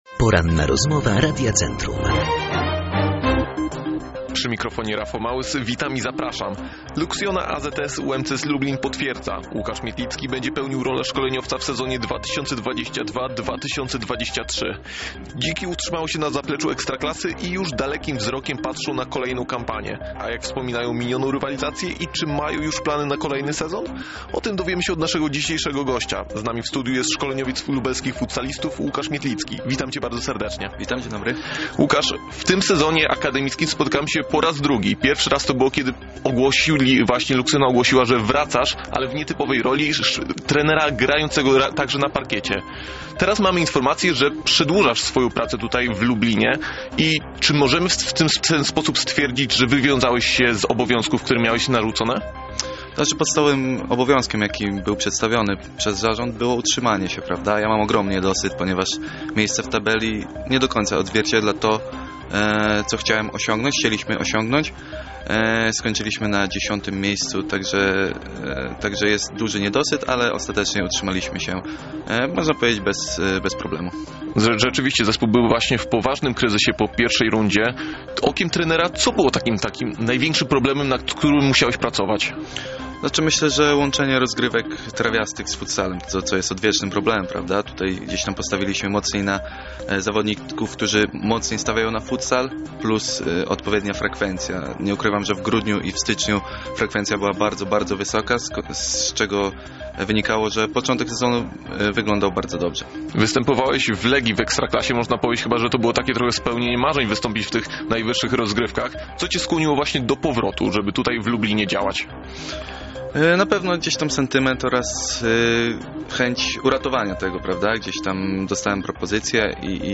O tym nieco więcej opowiedział nam w dzisiejszej Porannej Rozmowie Radia Centrum.